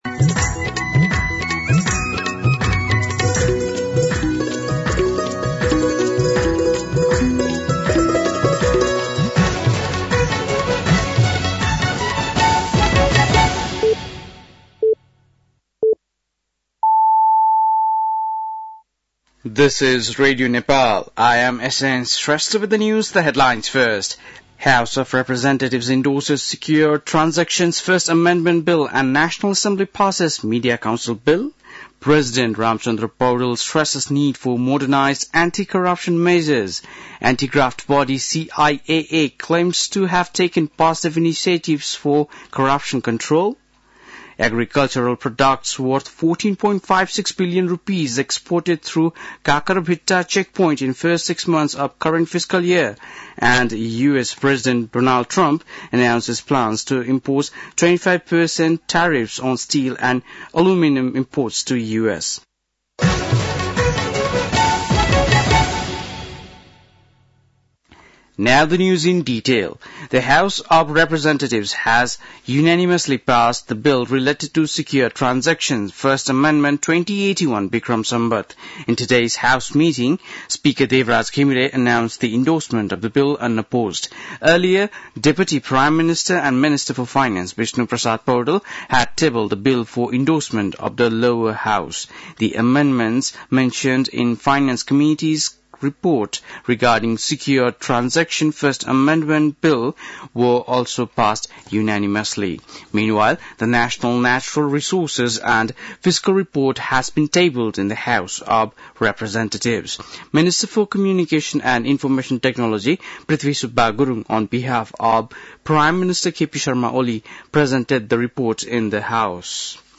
बेलुकी ८ बजेको अङ्ग्रेजी समाचार : २९ माघ , २०८१
8-PM-English-News-10-28.mp3